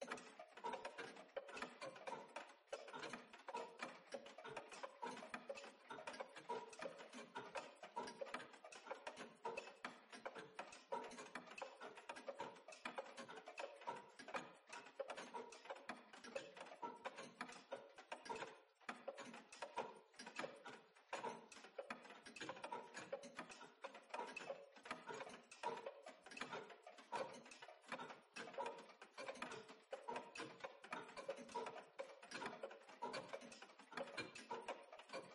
Здесь вы найдете саундтреки, фоновые шумы, скрипы, шаги и другие жуткие аудиоэффекты, создающие неповторимую атмосферу ужаса.
Звук множества часов - Тиканье